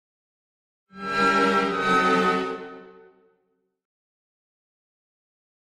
Strings|Violins
Violins, Short Reminder, Type 3 - Double,Semitone Descending